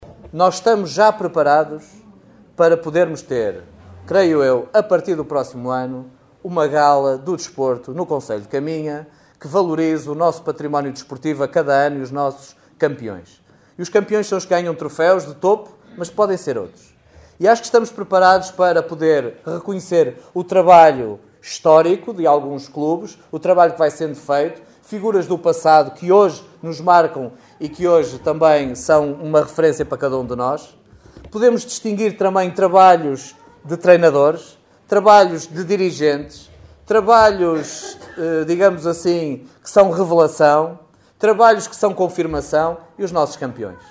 A afirmação foi feita no decorrer da cerimónia de entrega das faixas de campeão nacional aos atletas do SCC, que se realizou no salão nobre dos paços do concelho.
Miguel Alves a anunciar a realização de uma Gala Anual do Desporto em Caminha já a partir do próximo ano.